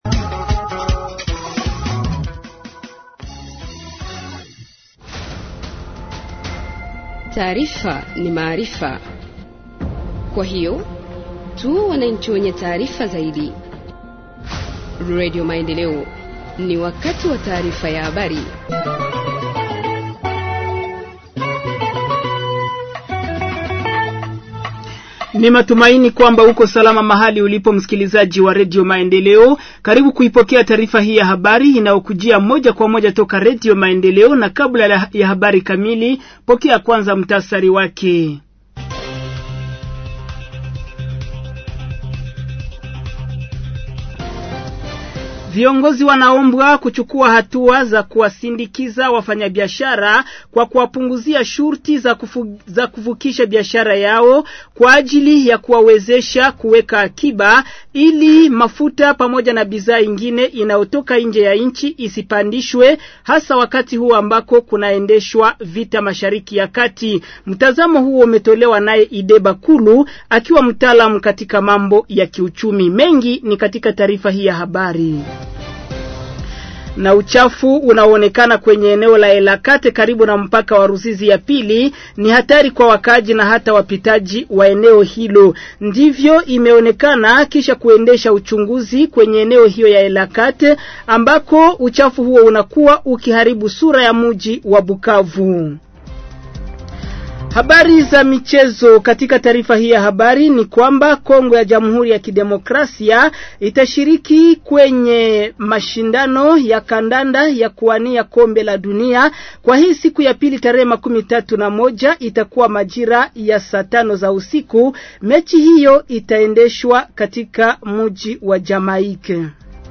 Journal en Swahili du 02 Avril 2026 – Radio Maendeleo